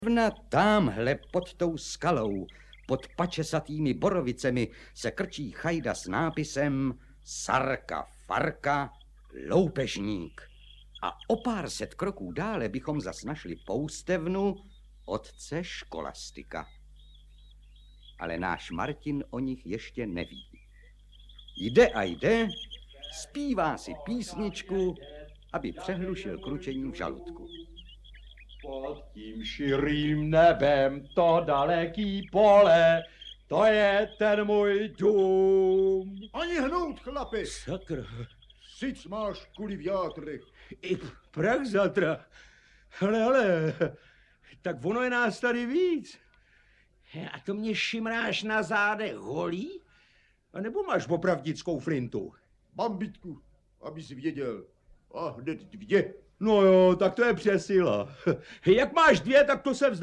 Audiobook
Read: František Filipovský